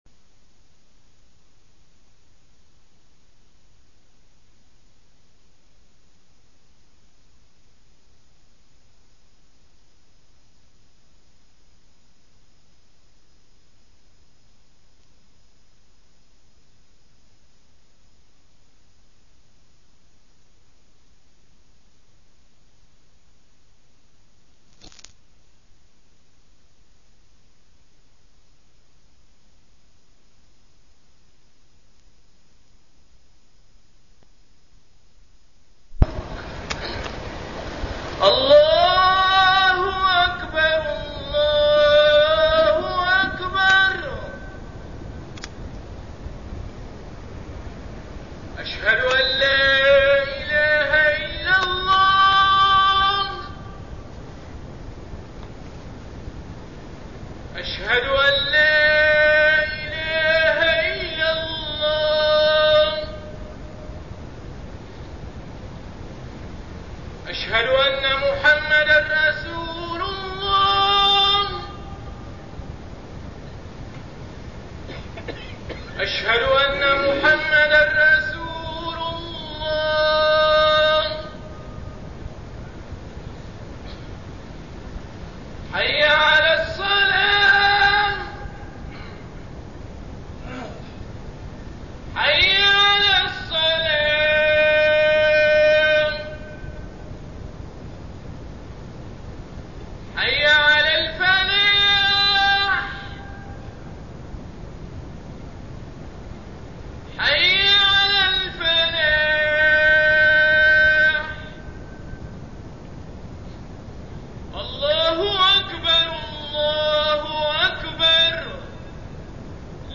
تاريخ النشر ٨ ذو القعدة ١٤١١ هـ المكان: المسجد الحرام الشيخ: محمد بن عبد الله السبيل محمد بن عبد الله السبيل فرائض الإسلام